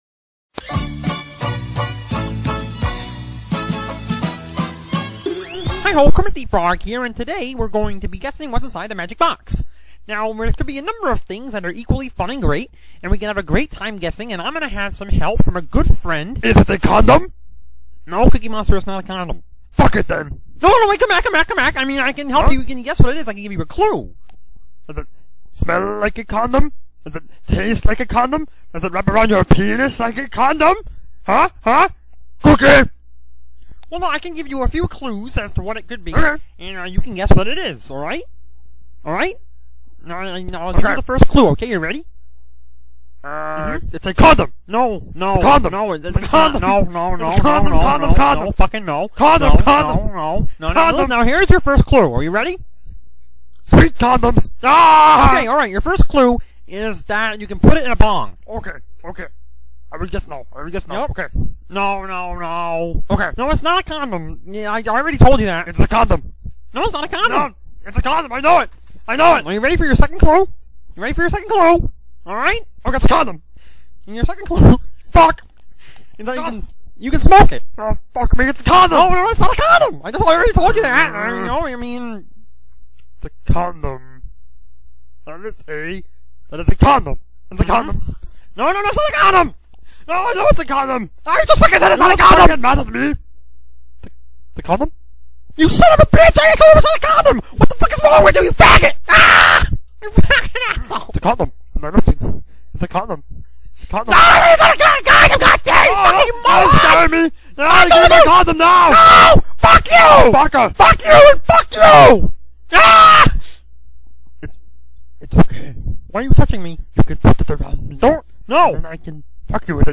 1960's folk